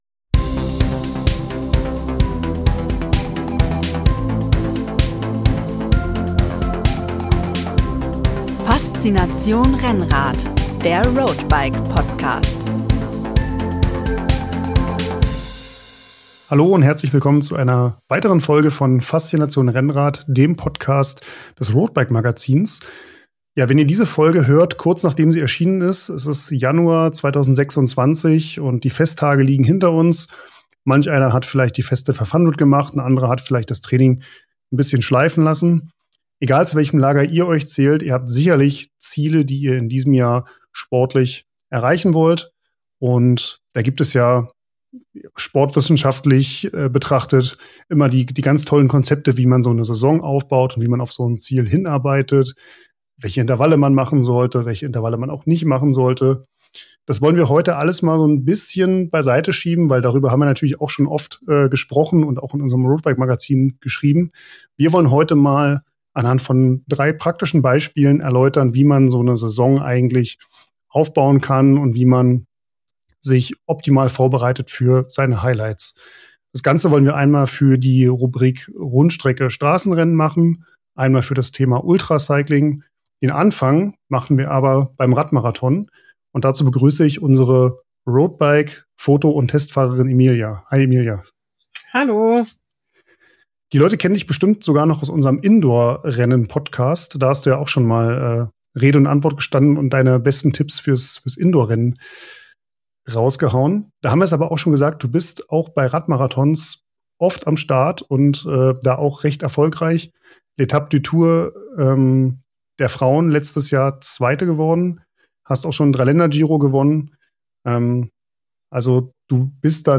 Florian Lipowitz im Exklusiv-Interview